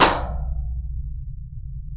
MIT_environmental_impulse_responses
Upload 16khz IR recordings
h043_Train_BostonTRedLine_4txts.wav